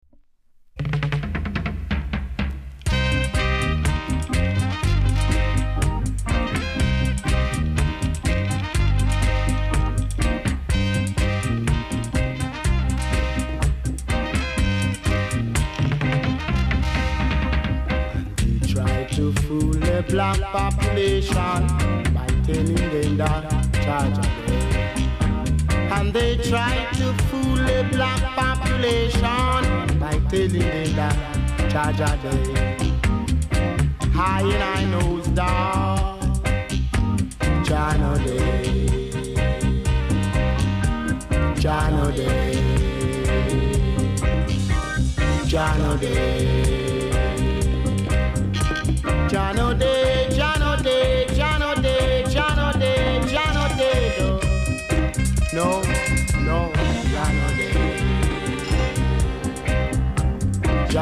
コメント ROOTS CLASSIC!!このUK REISSUE盤もレアです!!